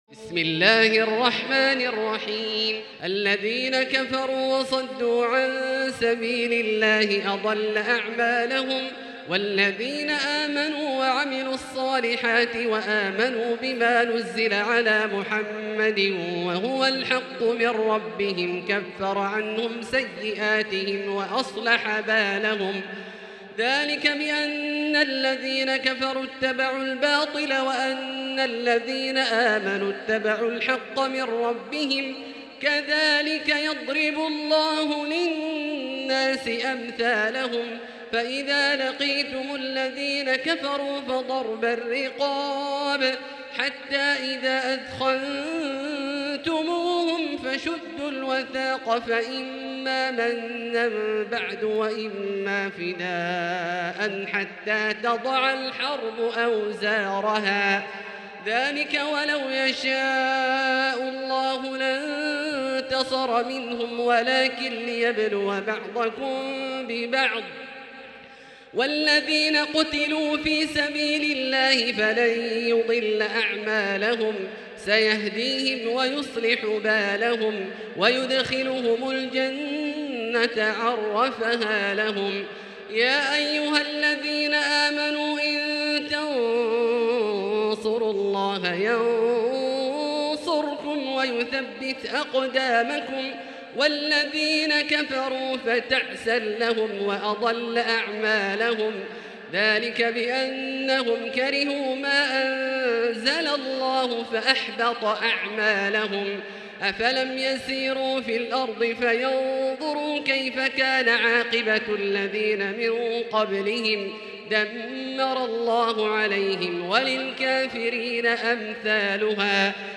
المكان: المسجد الحرام الشيخ: فضيلة الشيخ عبدالله الجهني فضيلة الشيخ عبدالله الجهني محمد